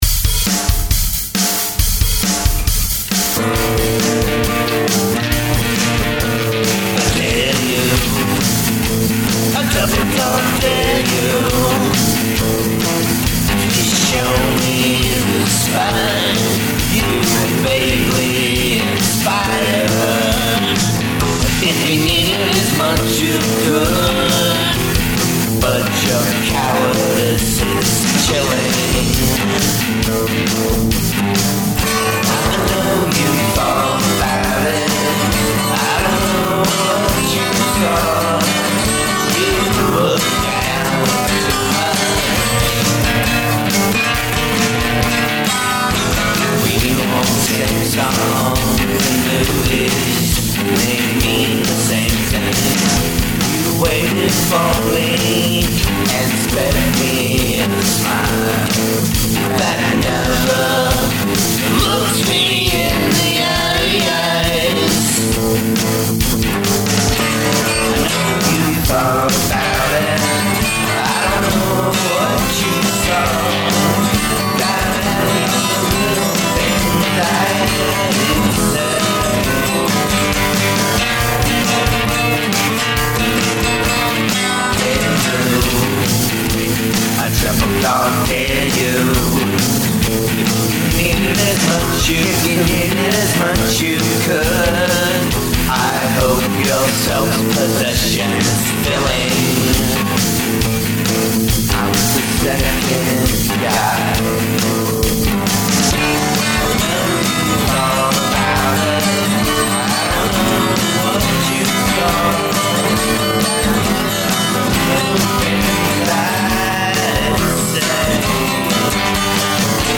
Now that I have a bass and other instruments, I was able to do a really
souped up version of it.